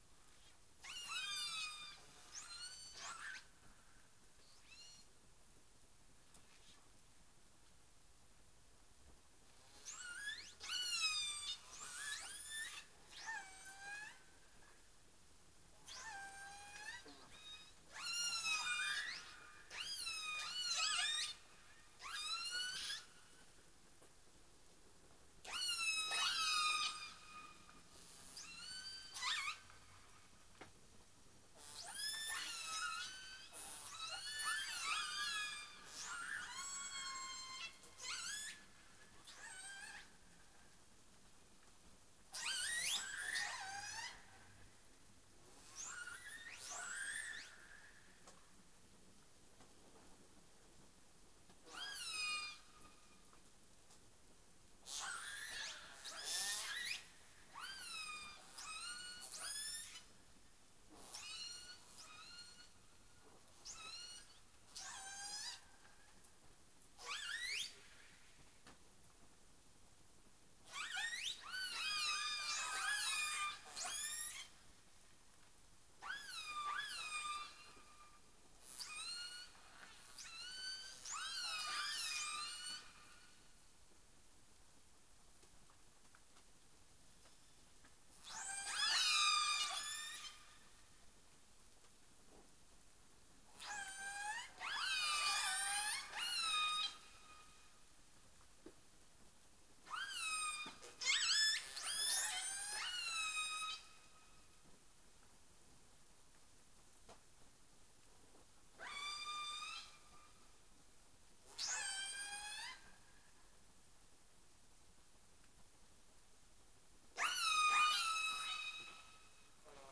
Nom commun : L’orque ou l’épaulard
Nom latin : Orcinus orca
Orcinus_orca_clean.wav